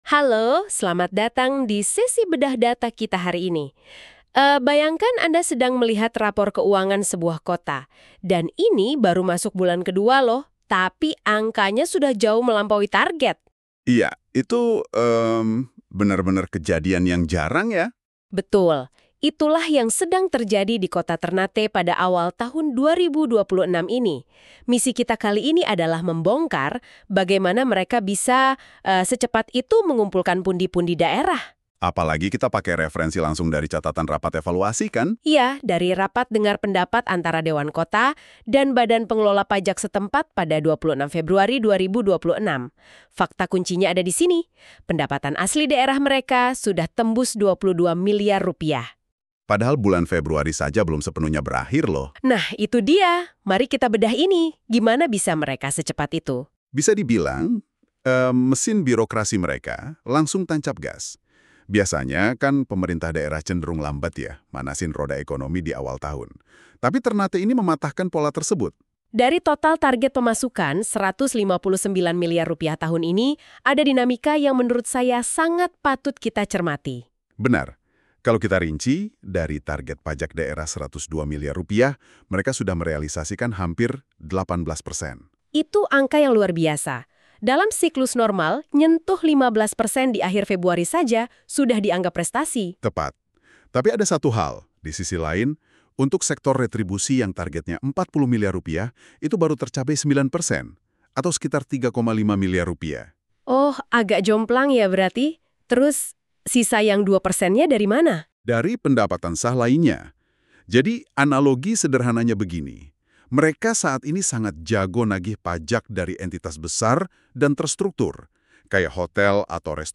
Audio podcast ini diproduksi menggunakan aplikasi NotebookLM by Google